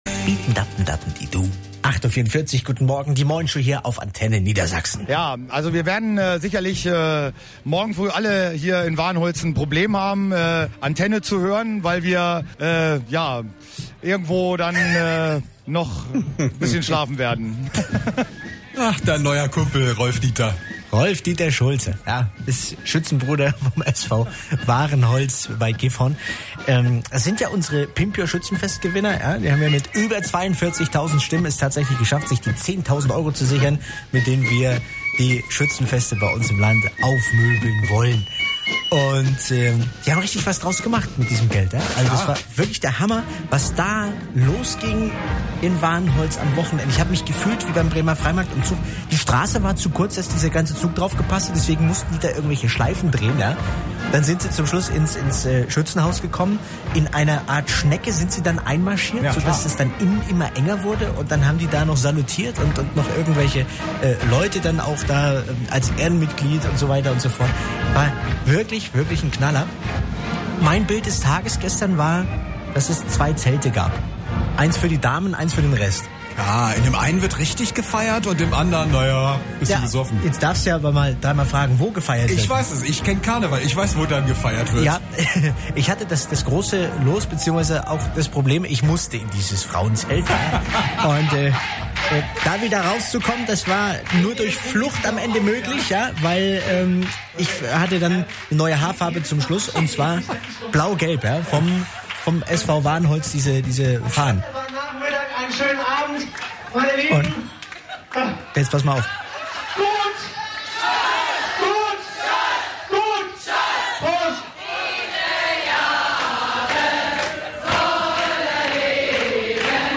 Mitschnitt Antenne Niedersachsen Montag, 01.06. 8:44 Uhr